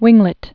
(wĭnglĭt)